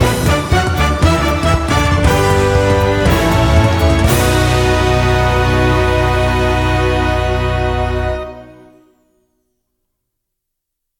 (gamerip)
(Jingle)